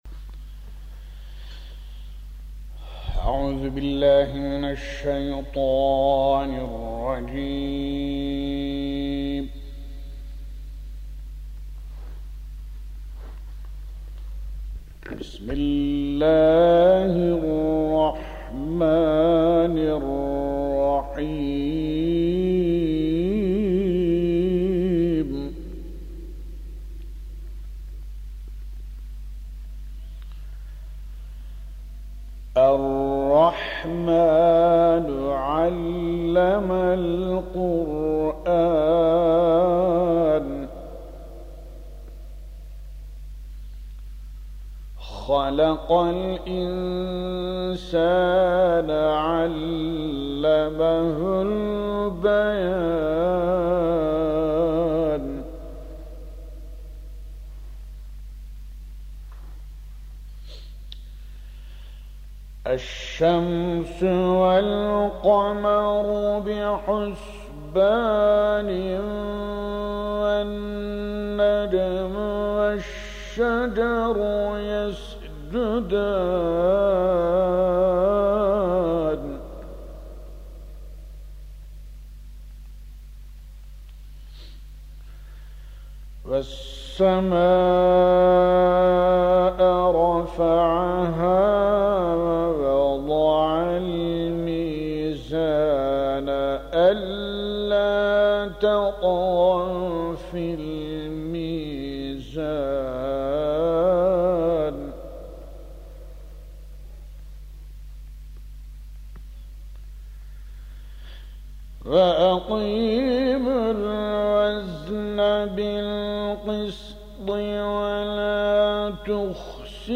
33 Rahman Suresi 1 - 30. Ayetler - 1989 - Kayıt: Digital